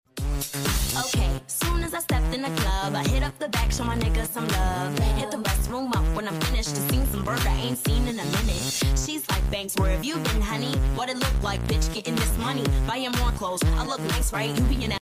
Hehehehe sound effects free download